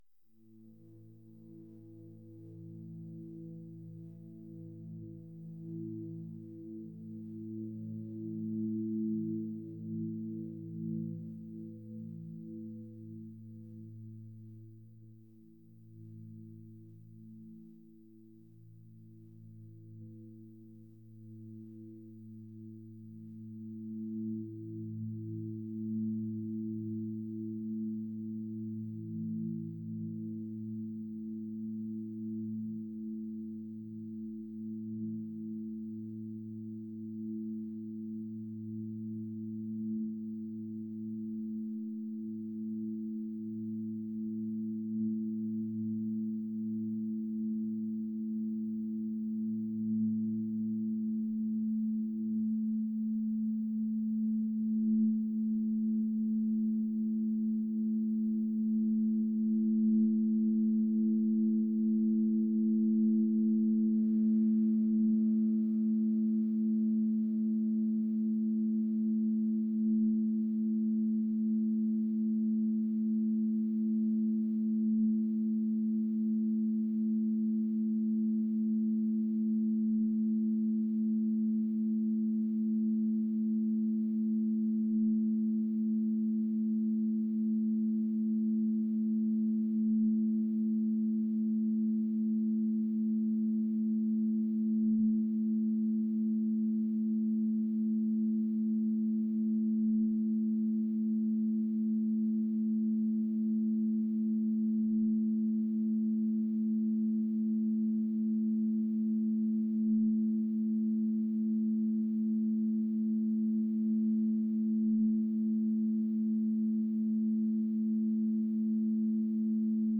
dreamy | atmospheric